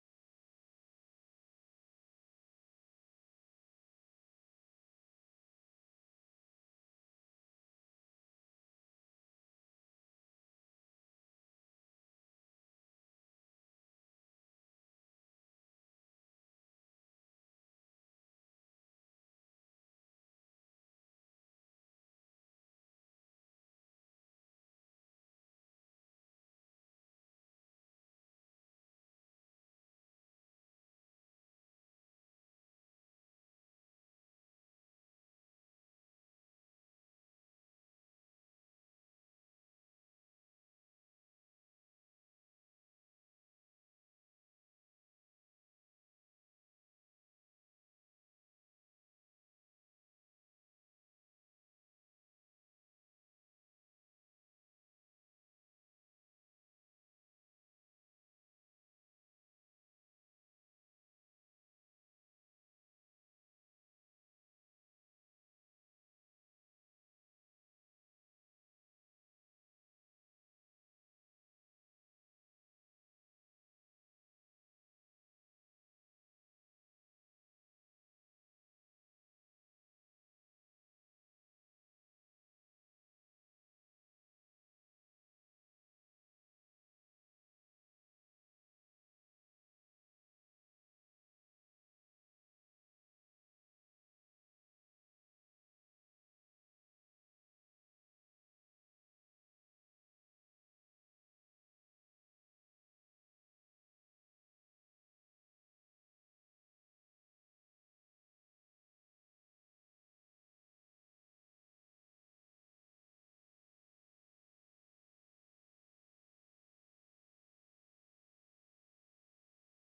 A number of .mp3 audio files of Csound works are included; these are demonstration performances only, and are in no way intended as definitive.
A CARILLON FOR CREATION (2025) for carillon or set of similar-sounding bells (with approximately two-octave chromatic range)
Alternatively for one-bell per performer ensemble, other carillon/bell-like instruments (e.g., organ chime/bells, handbell choir), single or multiple bell samples played by computer or Android technology, as well as automated versions including both realtime carillon and computerized (Csound) bell samples.
.wav [2 minutes; Carillon8Autoplay version, default parameters; download is 20.5MB]
Carillon8Autoplay.wav